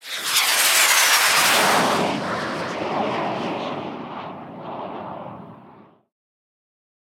woosh.ogg